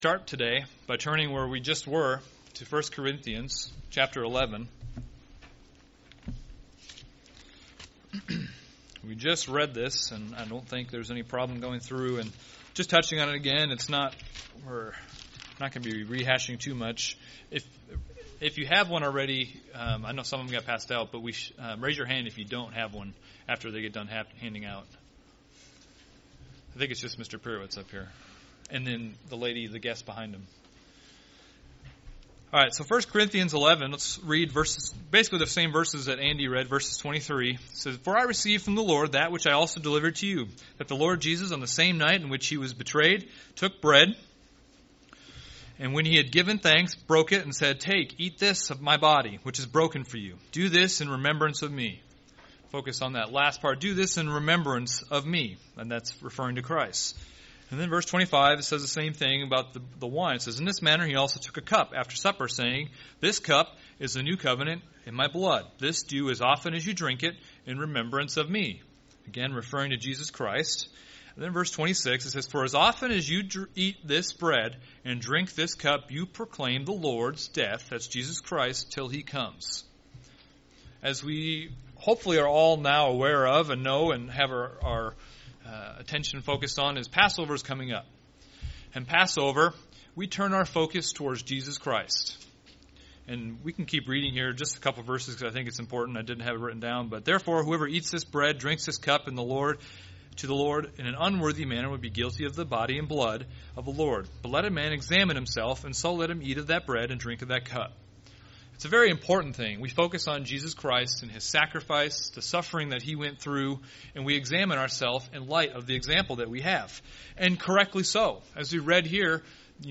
A sermon focusing on the Servant song in Isaiah 49:1-7.